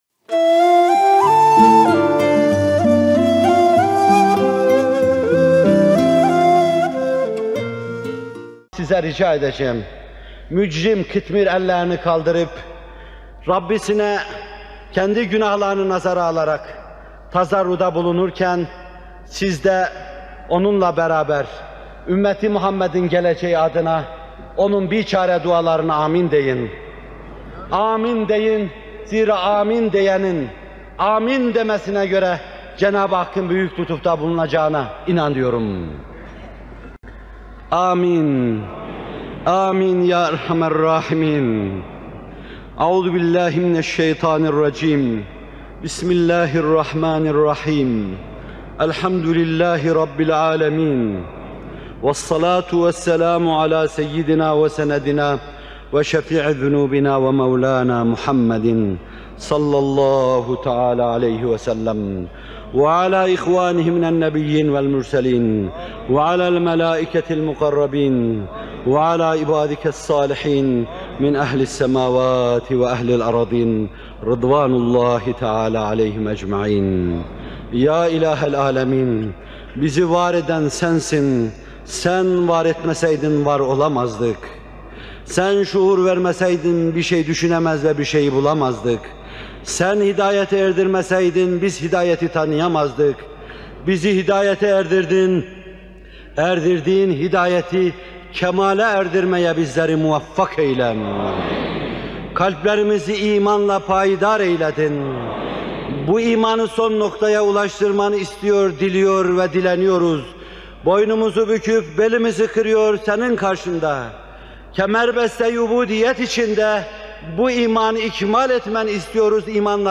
Muhterem Fethullah Gülen Hocaefendinin 2 Mayıs 1989 tarihinde Fatih Camii'nde yapmış olduğu Kadir Gecesi Duası..